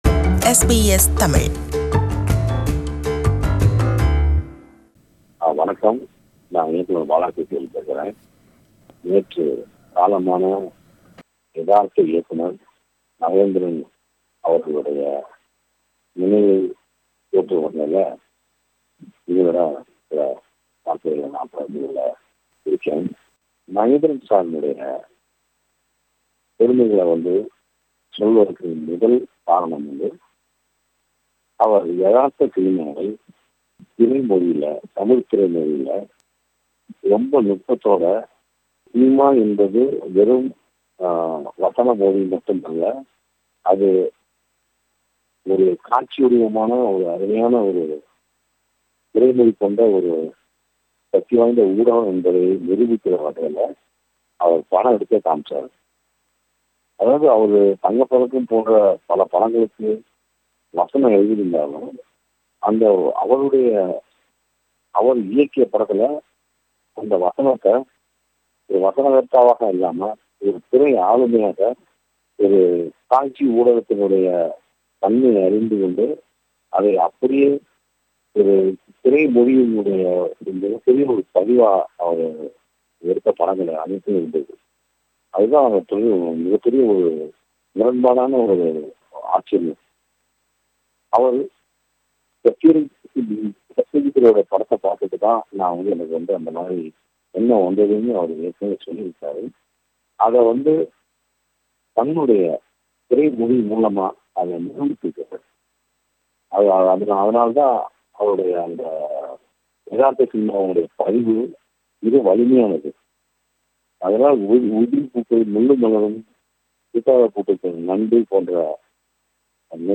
Director Balaji Sakthivel explains the contributions made by Director Mahendran.